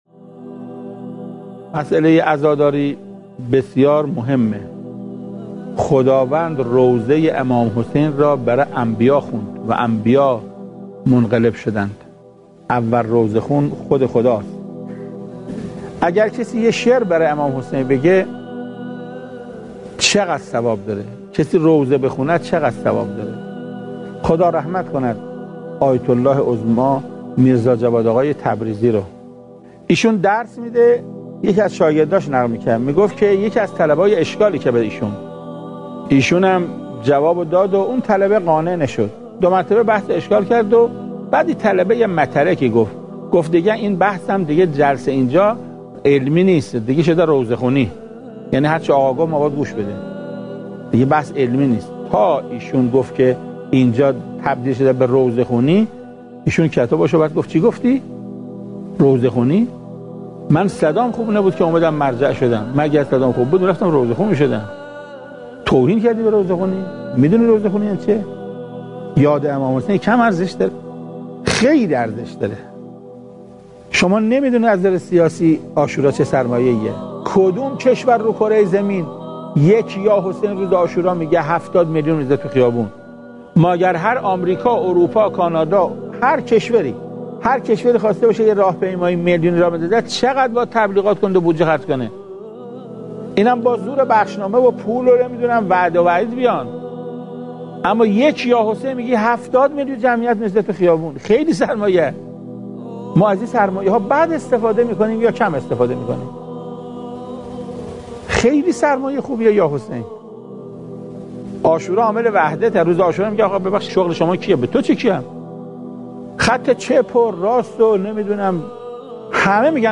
صوت سخنرانی حجت الاسلام و المسلمین قرائتی درباره قداست خدمت به امام حسین (علیه السلام) منتشر می شود.